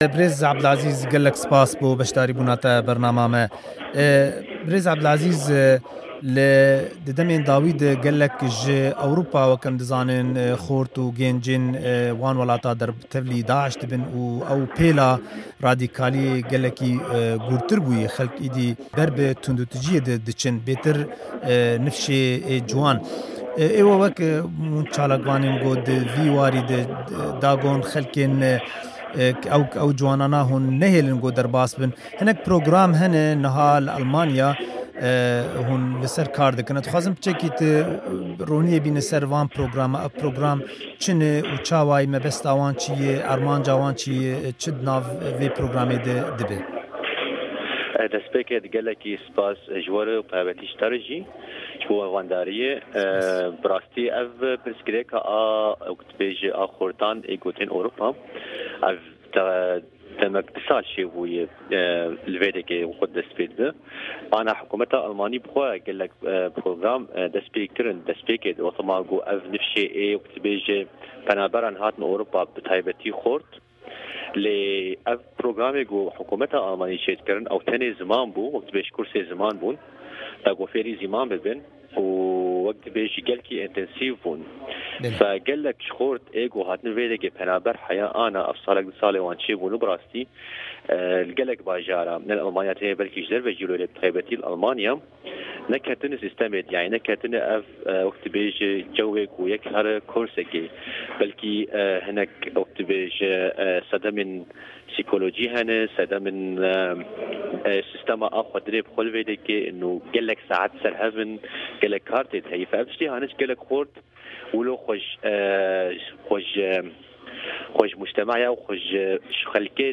Hevpeyvêna